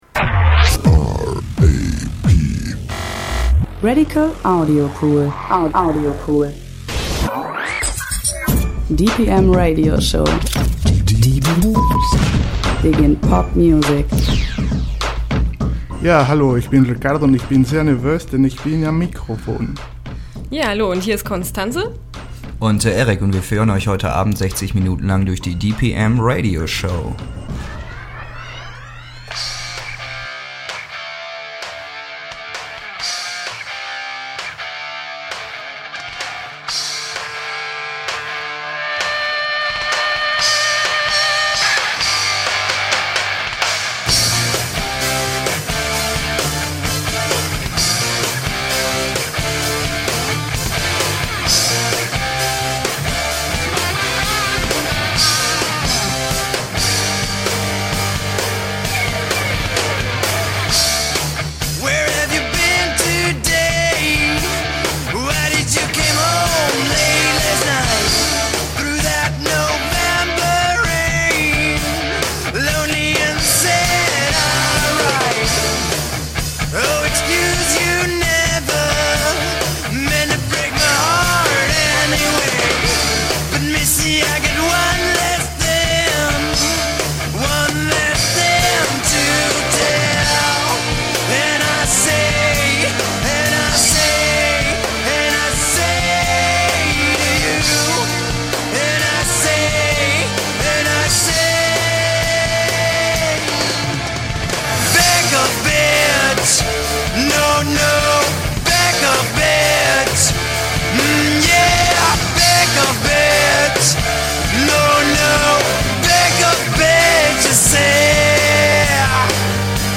Audiobeitrag über den integrativen Fußballverein SC Aleviten